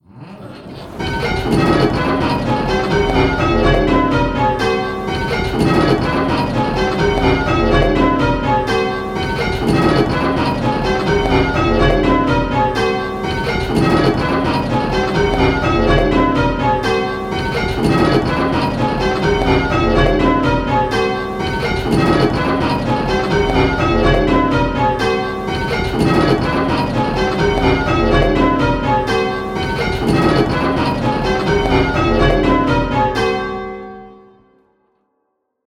Striking 8 Bell Rounds - Pebworth Bells
Striking 8 Bell Rounds - Round 2